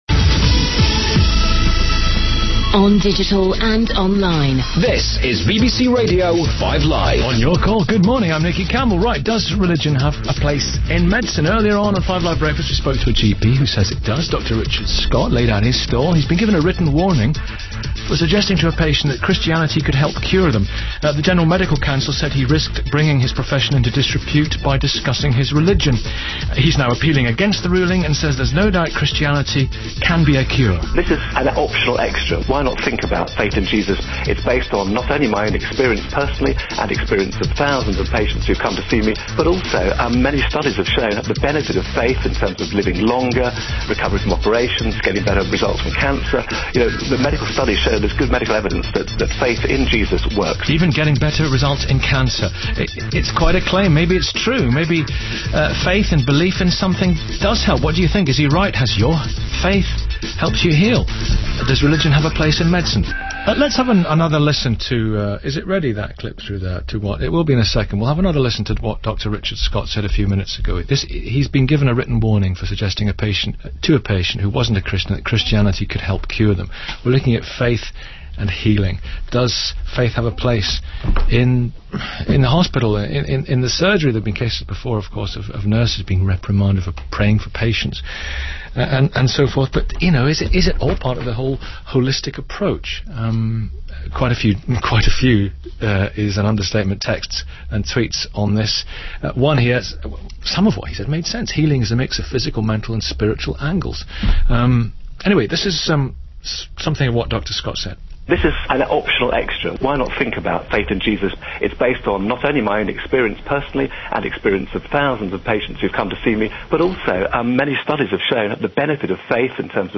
The program included many phone-ins, both for and against prayer for healing.
(I recorded the program, and edited out the news, weather, traffic and jingles.)